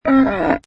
Camel 2 Sound Effect Free Download